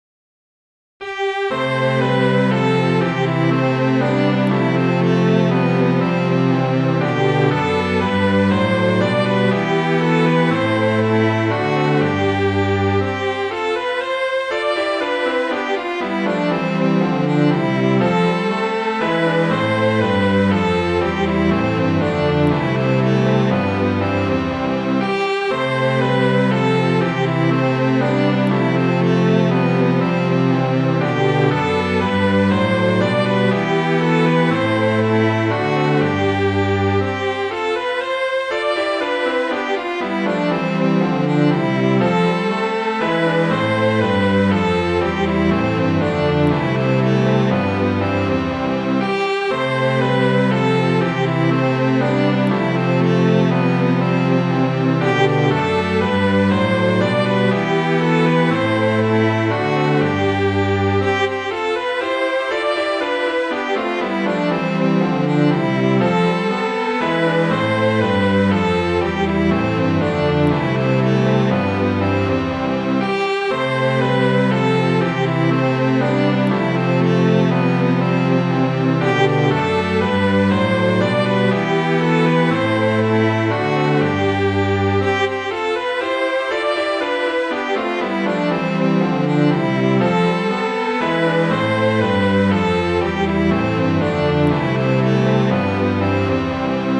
Midi File, Lyrics and Information to Pretty Polly Oliver